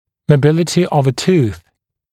[məu’bɪlətɪ əv ə tuːθ][моу’билэти ов э ту:с]подвижность зуба, мобильность зуба